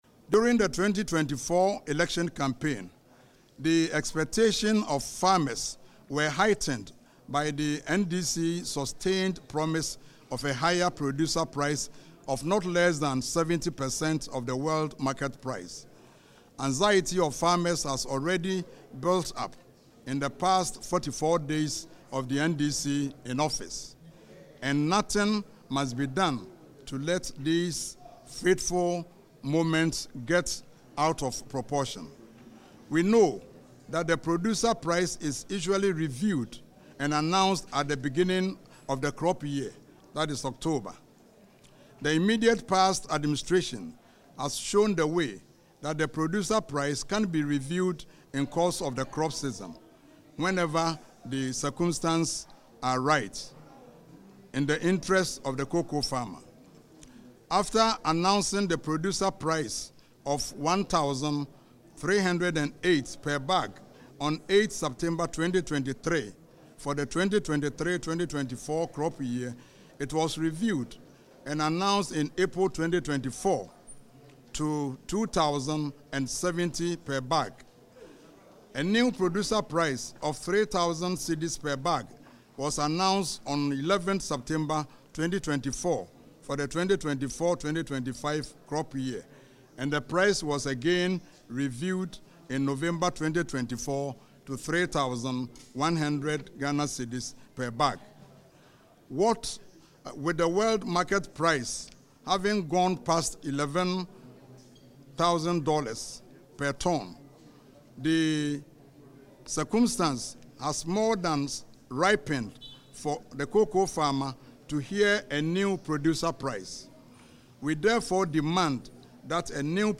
Dr Isaac Yaw Opoku, NPP MP for Offinso South, stated that the government must honour its promise.
“We insist that a new price not be set below GH₵7,000 per bag of cocoa,” he stated during a press conference in Parliament on Wednesday, February 19.